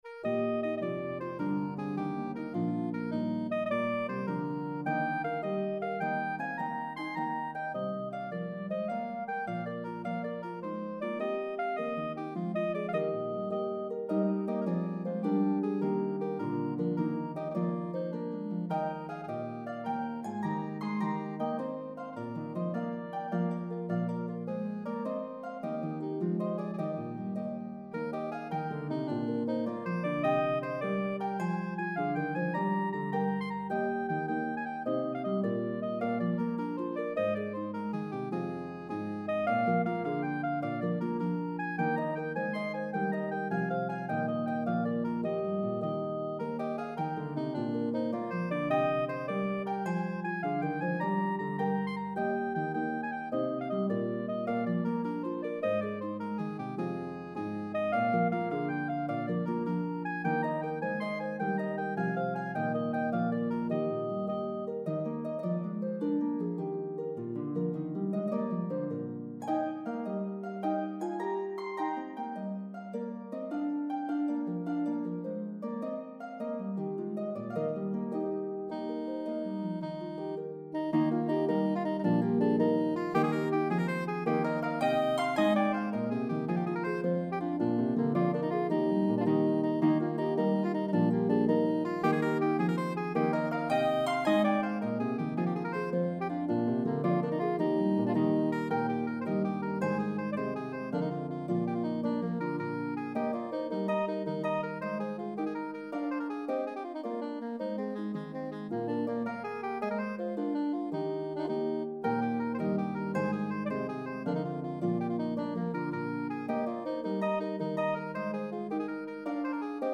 The medley progresses through 3 keys.